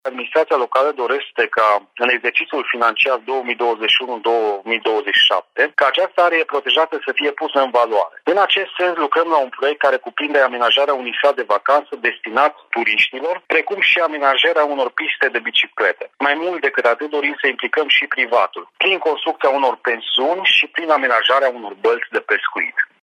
Administrația locală dorește să mobilizeze și investitorii privați pentru amenajarea unei bălți dar și pentru deschiderea de pensiuni în apropierea rezervației naturale Mlaștinile Satchinez, spune primarul Florin Cheaua.